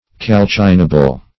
Calcinable \Cal*cin"a*ble\, a. That may be calcined; as, a calcinable fossil.